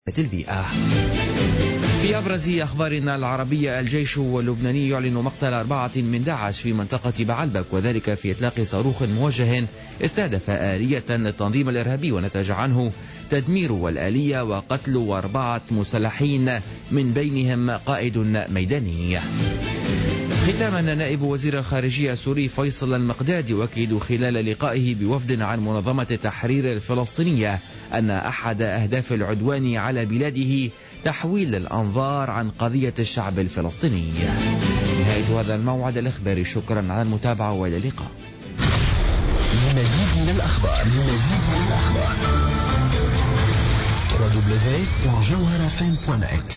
نشرة أخبار منتصف الليل ليوم الاربعاء 16 ديسمبر 2015